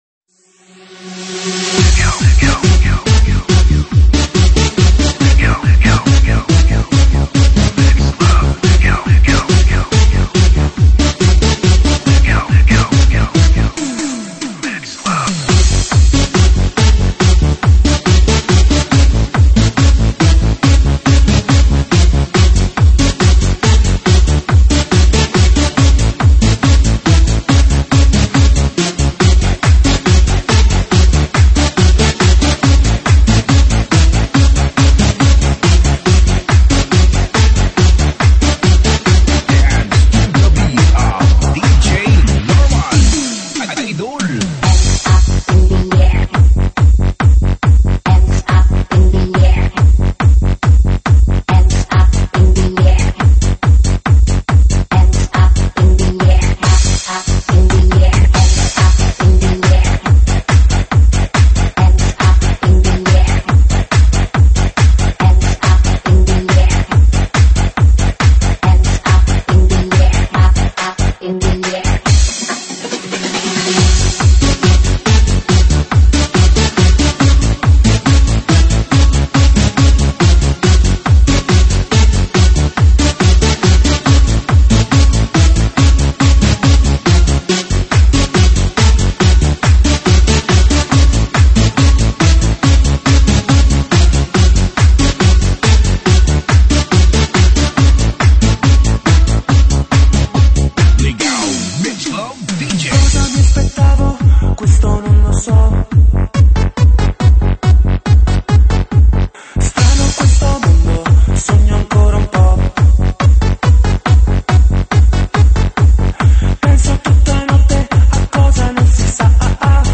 【禁止外传】车载音乐REMIX 手机播放